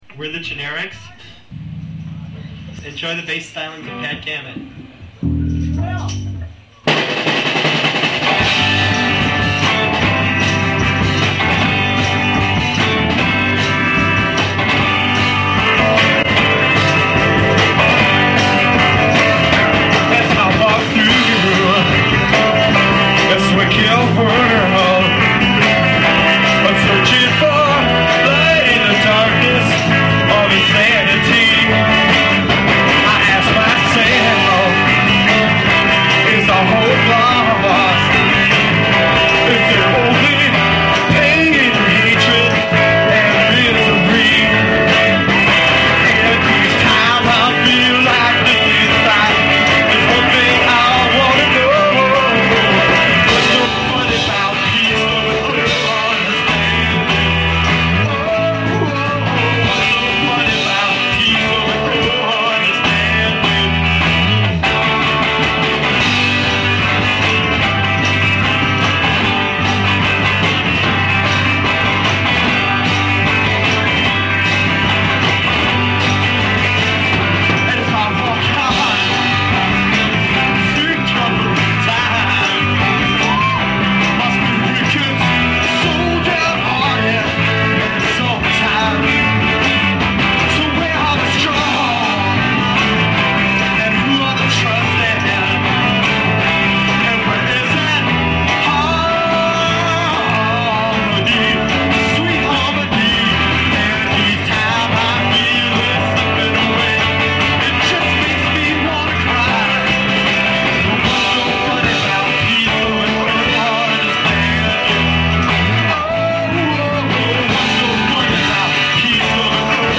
guitar
drums
keyboards
bass
saxophone